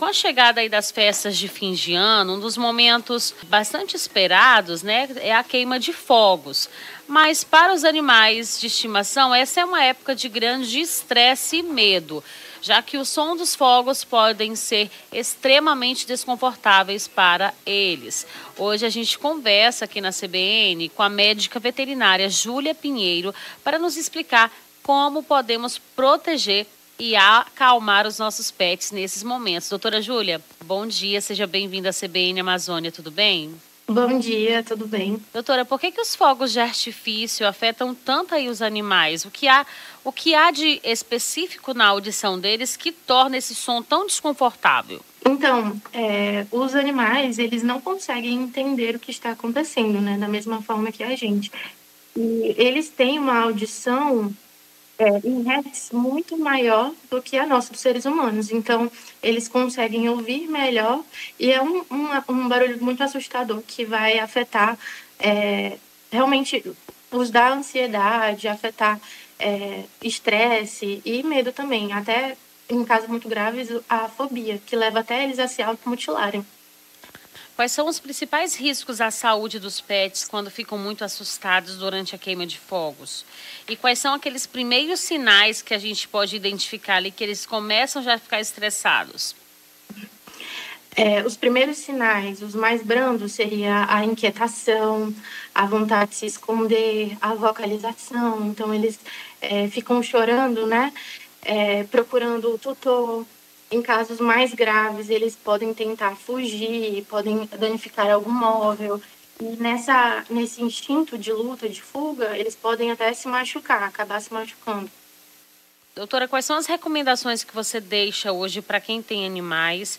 Rio Branco
Nome do Artista - CENSURA - ENTREVISTA CUIDADOS PETS FOGOS DE ARTIFÍCIOS (31-12-24).mp3